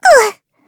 Taily-Vox_Damage_kr_02.wav